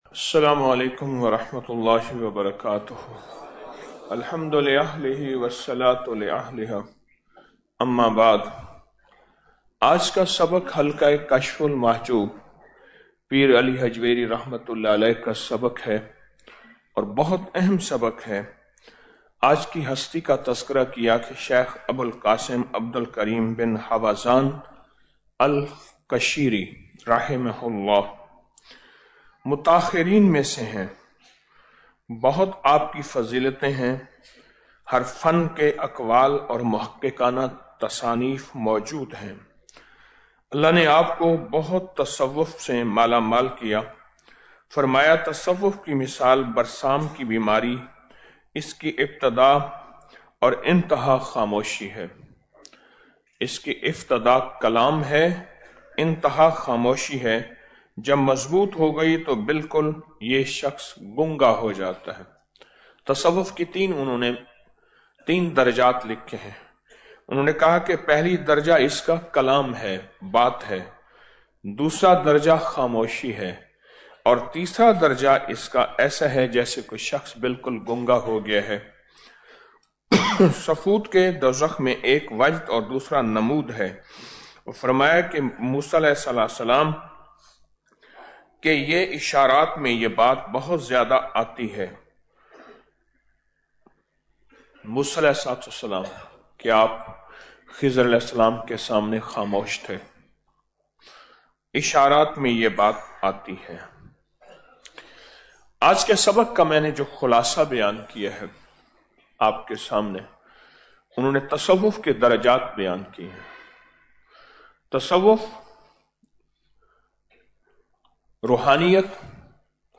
Audio Speech - 13 Ramadan After Salat Ul Fajar - 14 March 2025